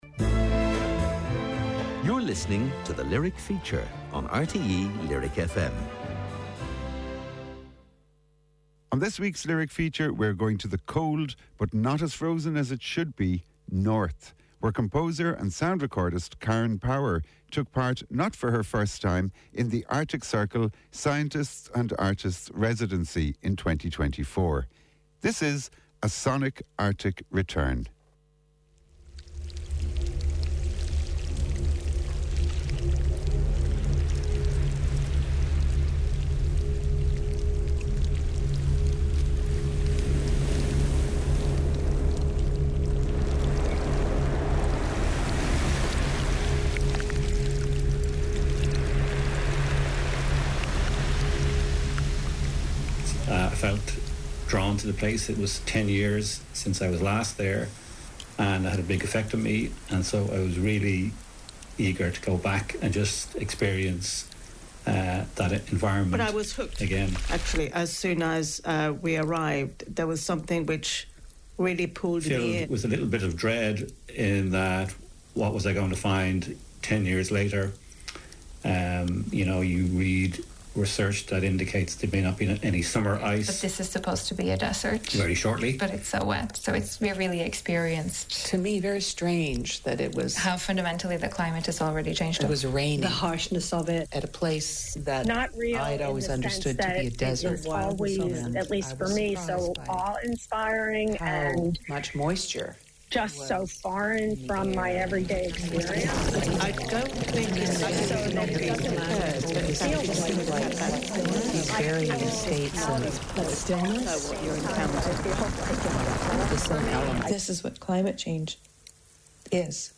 Irish broadcaster RTÉ lyric fm's weekly documentary slot. Programmes about music, literature, visual arts and other areas where creativity is manifest.